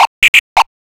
OLDRAVE 5 -R.wav